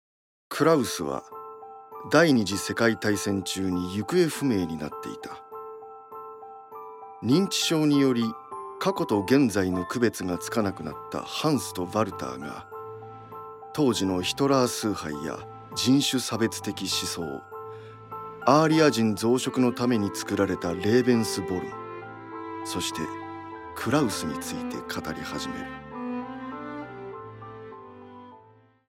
ボイスサンプル
ナレーション